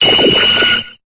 Cri de Lewsor dans Pokémon Épée et Bouclier.